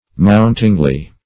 mountingly - definition of mountingly - synonyms, pronunciation, spelling from Free Dictionary Search Result for " mountingly" : The Collaborative International Dictionary of English v.0.48: Mountingly \Mount"ing*ly\, adv. In an ascending manner.